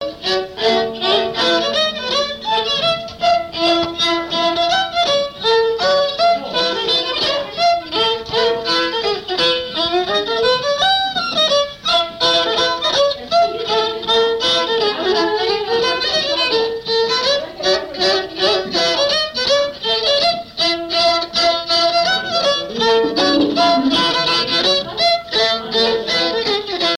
Mémoires et Patrimoines vivants - RaddO est une base de données d'archives iconographiques et sonores.
danse : scottich sept pas
Pièce musicale inédite